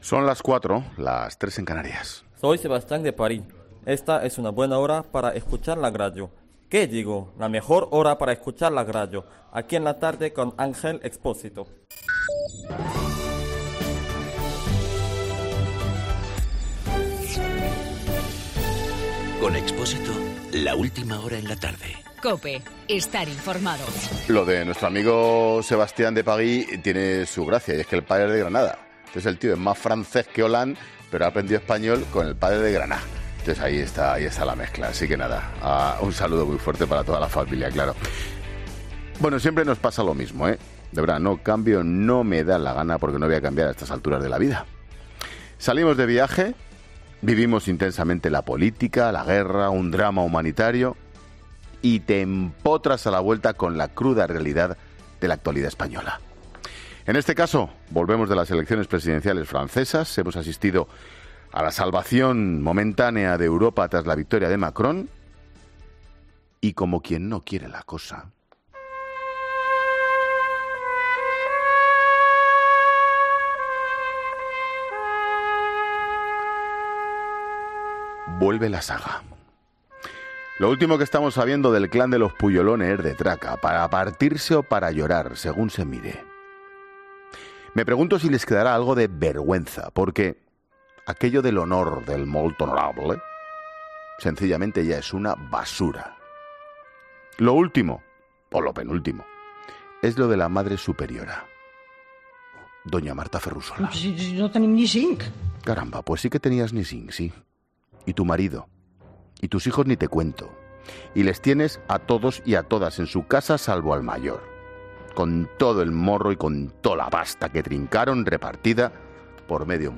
AUDIO: Monólogo 16 h.